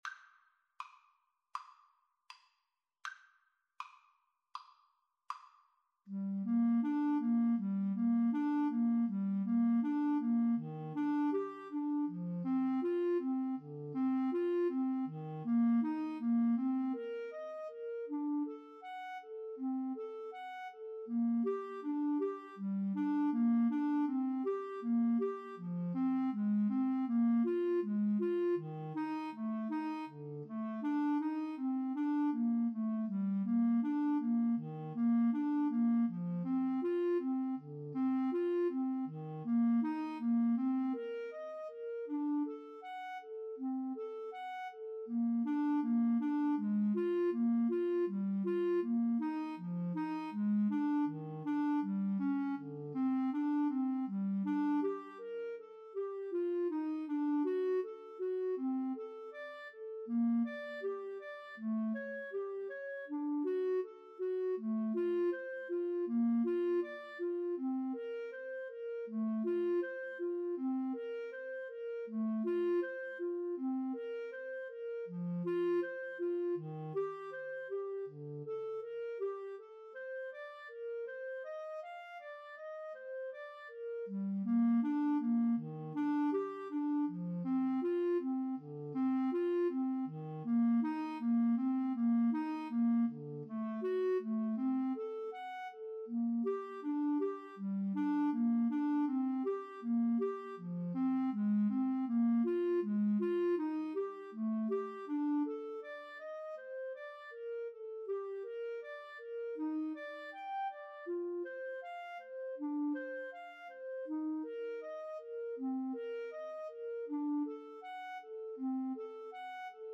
Play (or use space bar on your keyboard) Pause Music Playalong - Player 1 Accompaniment reset tempo print settings full screen
G minor (Sounding Pitch) (View more G minor Music for Clarinet-French Horn Duet )
Andante